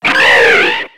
Cri de Gueriaigle dans Pokémon X et Y.